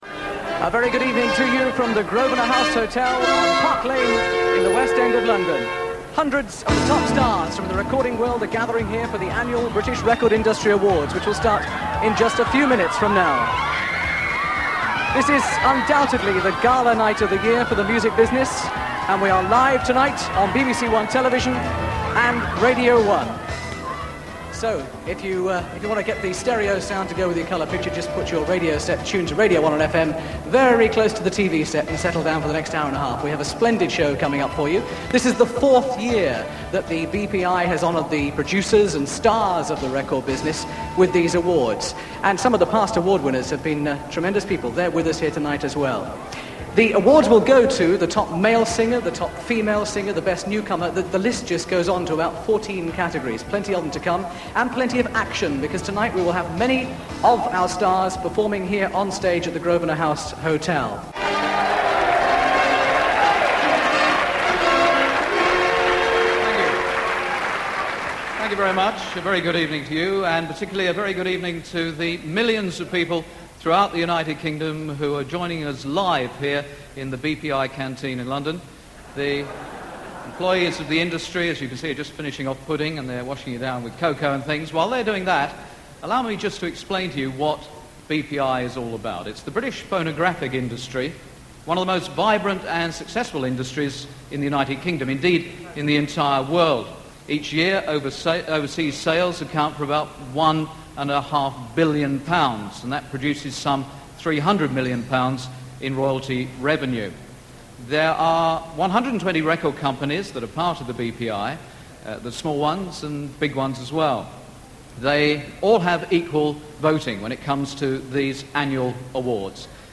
The BRITS were in their infancy when this awards ceremony was broadcast on Radio 1 and BBC1 on 11 February 1985, although back then they were referred to as the BPI Awards and the programme itself was billed as The British Record Industry Awards.
In this recording you’ll hear Noel Edmonds introducing proceedings from The Grosvenor House Hotel in London, award winners Alison Moyet, Sade, Holly Johnson and Prince. The opening welcome for radio listeners comes from Mike Smith and on voiceover duty is Tommy Vance.